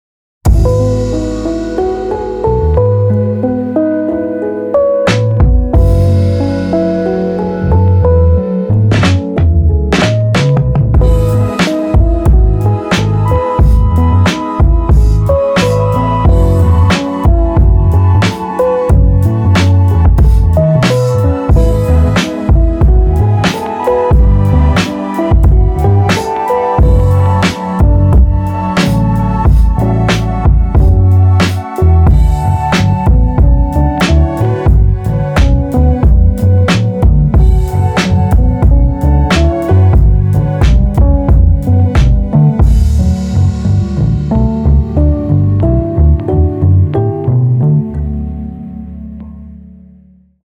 Teletone Audio Vespertone 是一个虚拟乐器插件，它可以让你使用 Wurlitzer, Vibraphone, Celeste 和 Rhodes 四种声学乐器的原始声音，并将它们变形为抽象的版本。